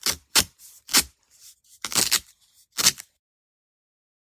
tape_pull1.ogg